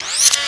SFX Rnb.wav